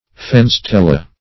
Search Result for " fenes-tella" : The Collaborative International Dictionary of English v.0.48: Fenes-tella \Fen`es-tel"la\, n. [L., dim. of fenestra ? window.]